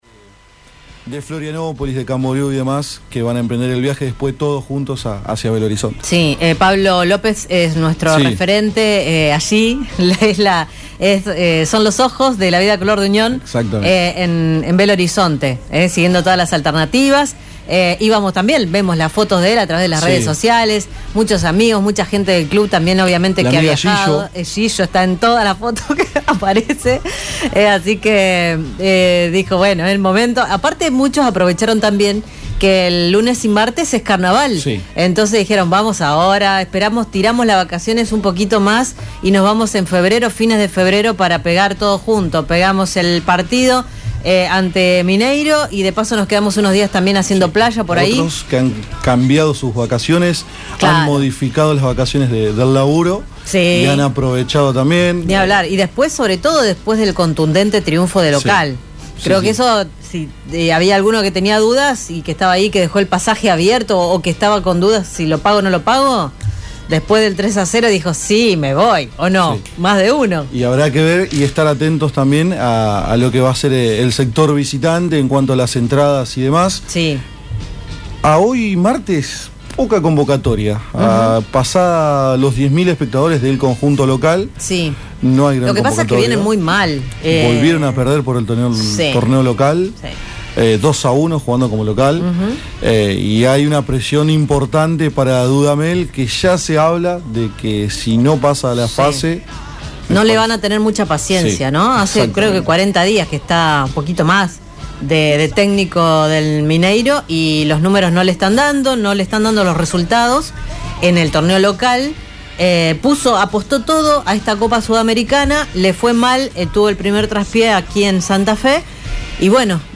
El entrenador de Unión habló en la previa del histórico encuentro ante Atlético Mineiro por la vuelta de la primera fase de la Copa Sudamericana.
Por tal motivo, Leonardo Carol Madelón habló en la “La Vida Color de Unión”, el programa oficial del club rojiblanco emitido por Radio EME, y en todas sus emisoras.